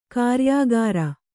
♪ kāryāgāra